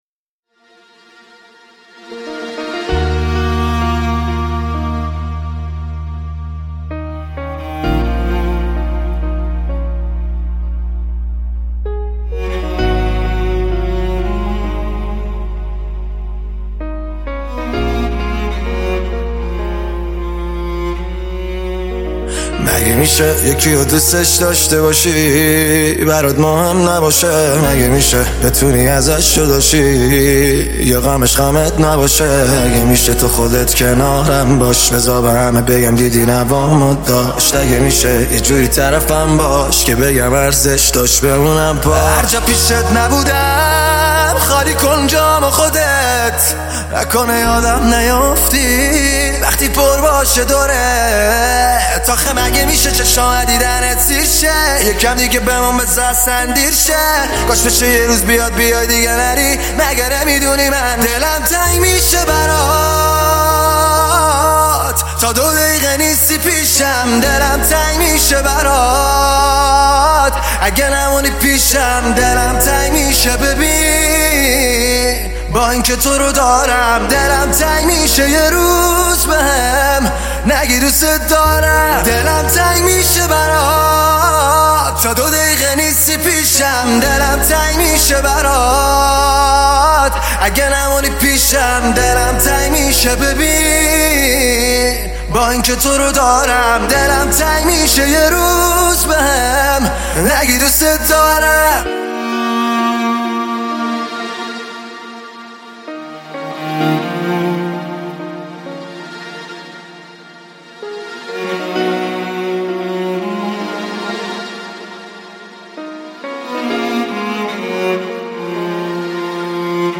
پاپ عاشقانه عاشقانه غمگین